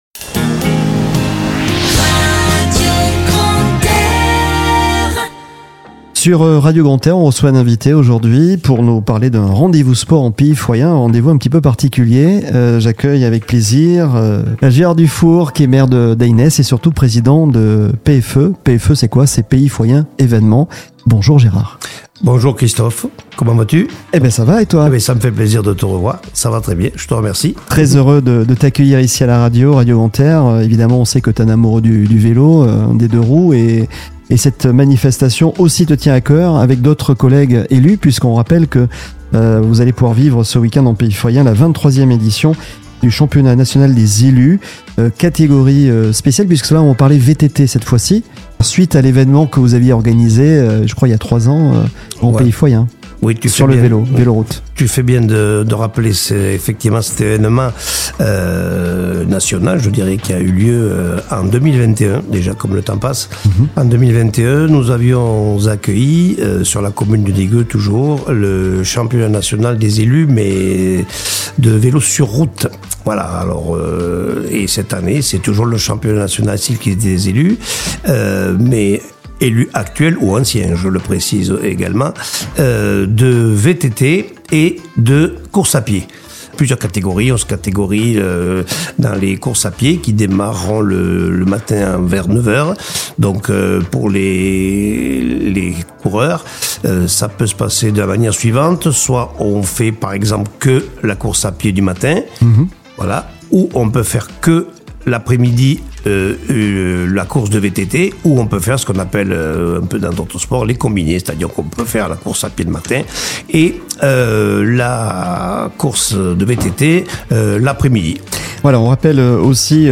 Gérard Dufour, le maire d'Eynesse et président de l'association P.F.E, revient sur l'évènement au micro de Radio Grand "R".